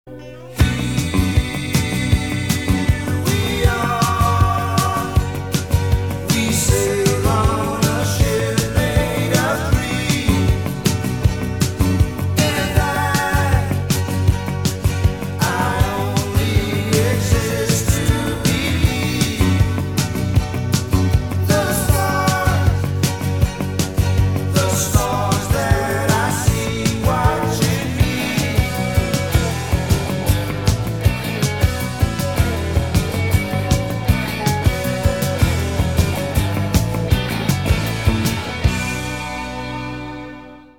спокойные
РОК-Н-РОЛЛ